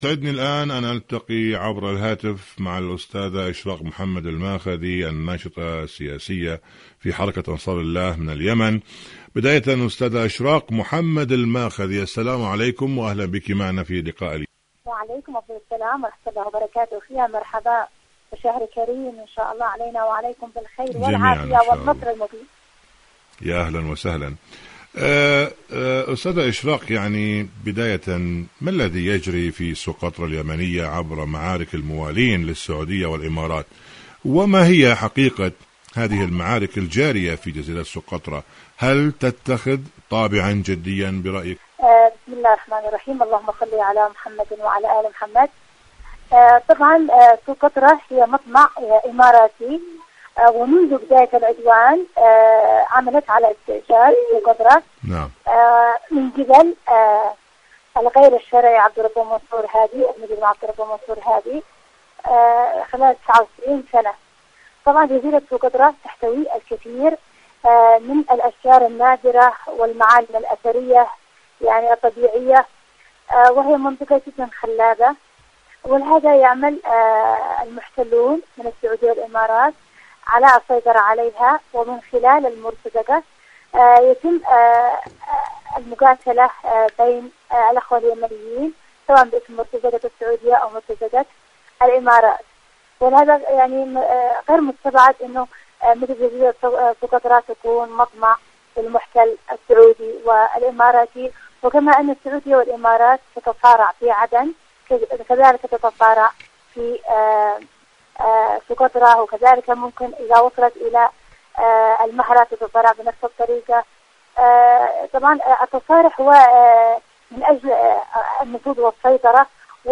مقابلات إذاعية الارتزاق الإماراتي الارتزاق السعودي